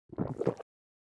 喝水喉结的声音－YS070517.wav
通用动作/01人物/02普通动作类/喝水喉结的声音－YS070517.wav